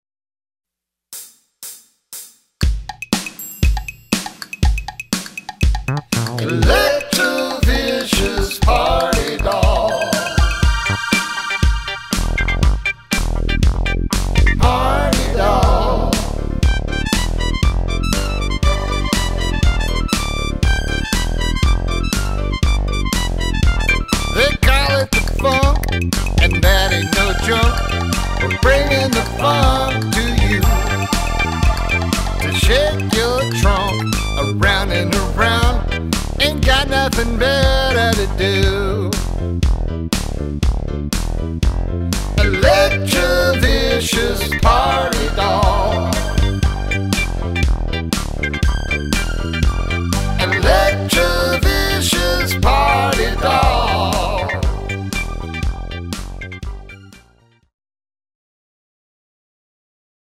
funkadelicious